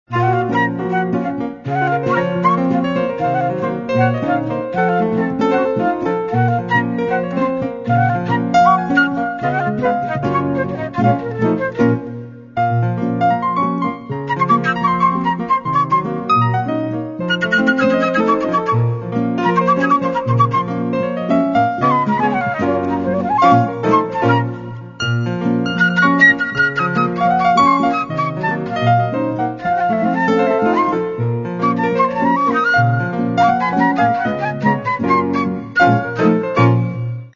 Каталог -> Джаз и около -> Сборники, Джемы & Live